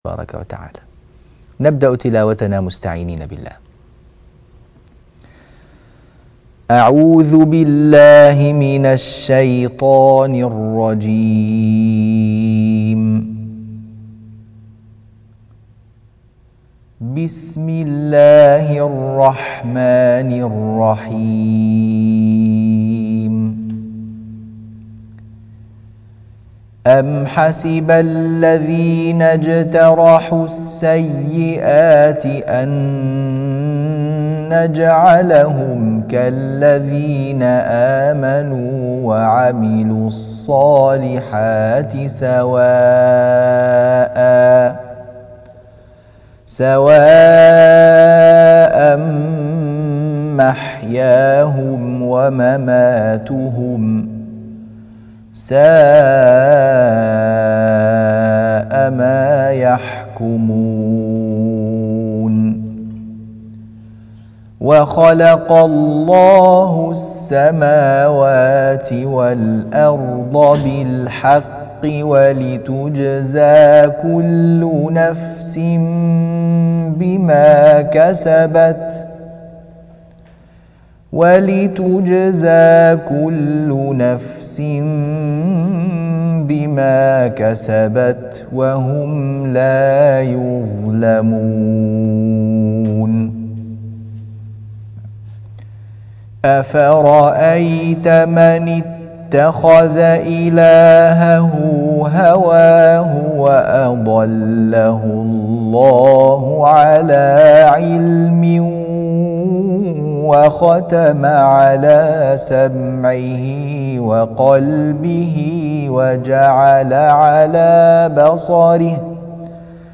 تلاوة وتنبيهات سورة الجاثية الآيات 21 - 26 - تصحيح التلاوة